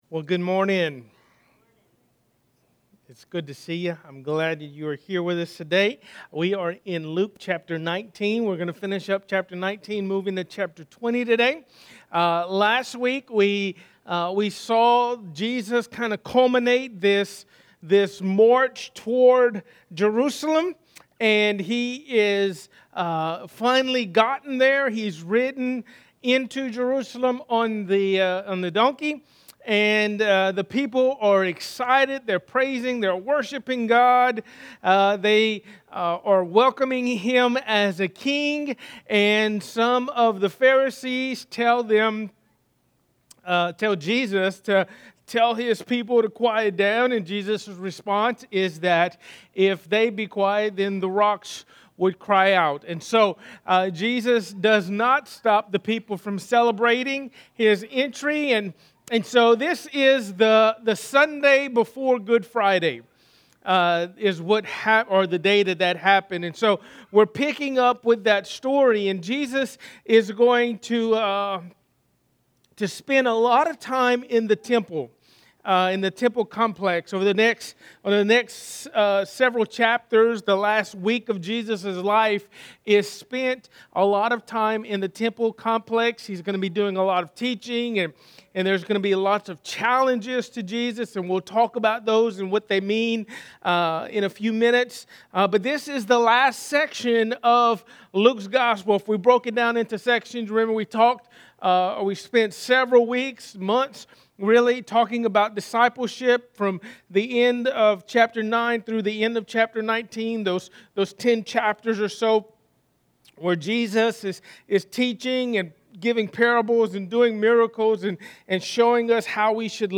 A message from the series "Luke."